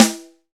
Index of /90_sSampleCDs/Roland L-CDX-01/SNR_Rim & Stick/SNR_Rim Modules
SNR RINGER07.wav